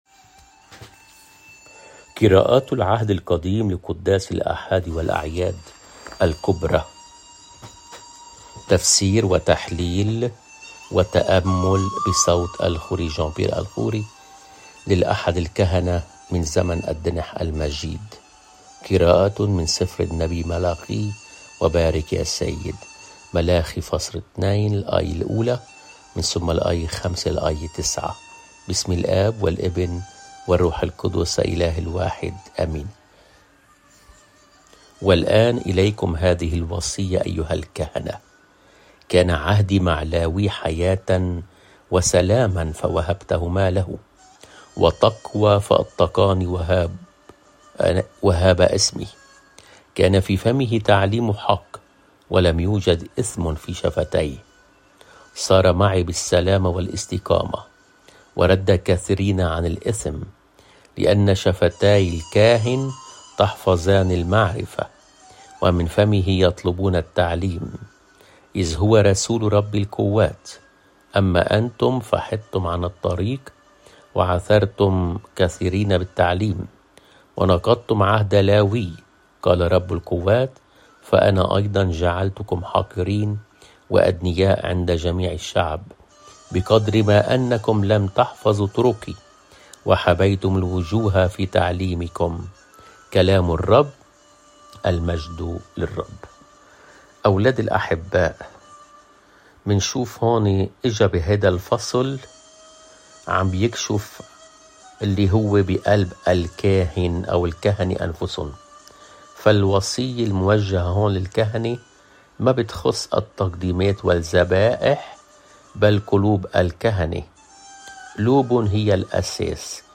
قراءة من العهد القديم قِراءةٌ مِن سِفْرِ النبيّ ملاخي (ملاخي ٢ / ١ + ٥ - ٩)